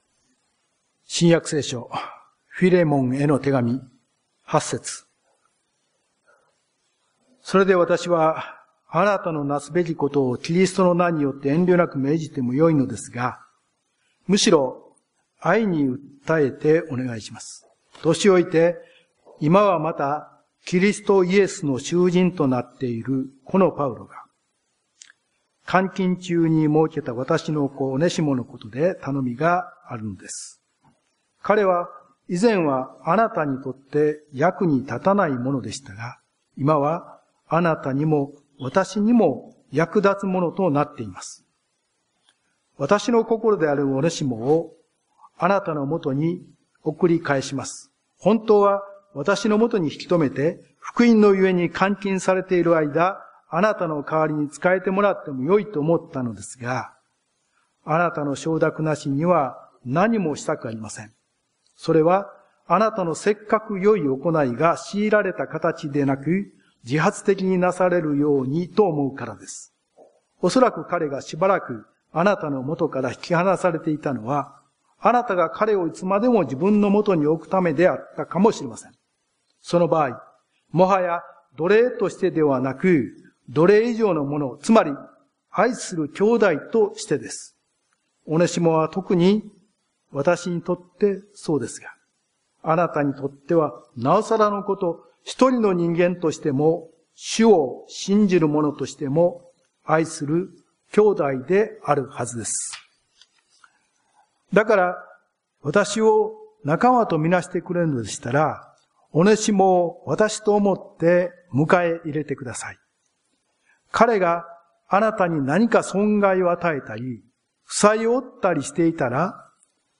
聖書朗読(1MB)